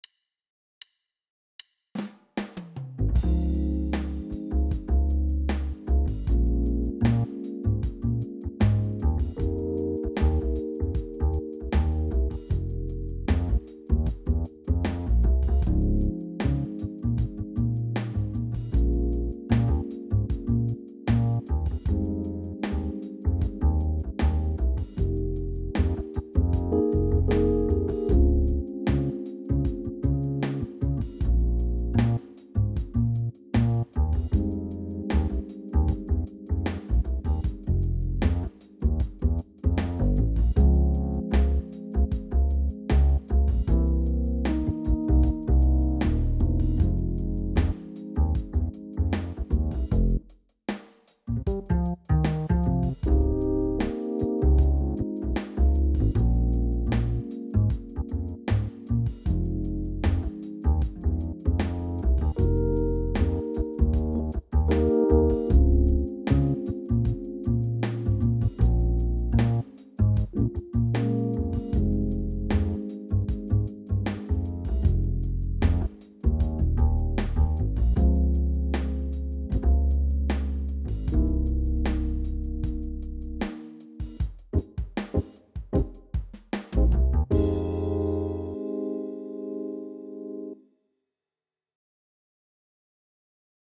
PLAYBACK JAMS
Soul Groove in Db-Bm-Fm